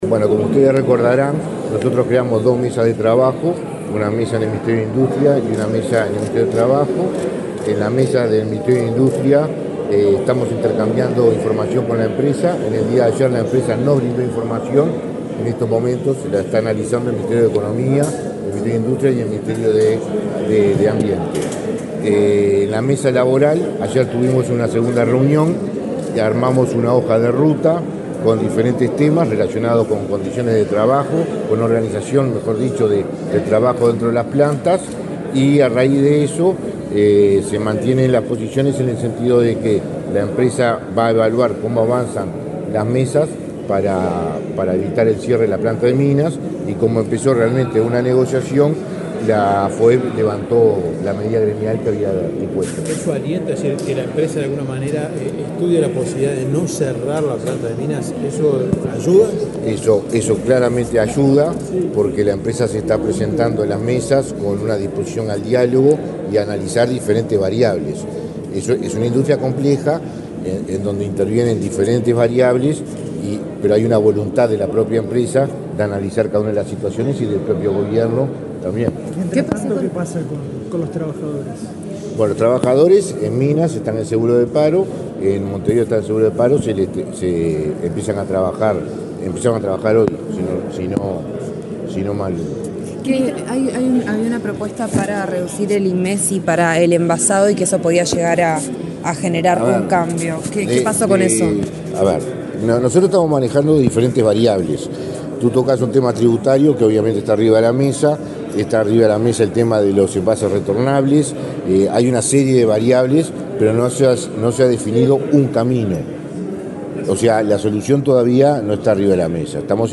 Declaraciones del ministro de Trabajo, Mario Arizti
El ministro de Trabajo, Mario Arizti, dialogó con la prensa sobre diversos temas del sector, luego de participar, este viernes 24 en la Torre
Declaraciones del ministro de Trabajo, Mario Arizti 24/05/2024 Compartir Facebook X Copiar enlace WhatsApp LinkedIn El ministro de Trabajo, Mario Arizti, dialogó con la prensa sobre diversos temas del sector, luego de participar, este viernes 24 en la Torre Ejecutiva, en la presentación del nuevo Sistema Nacional de Servicios de Empleo.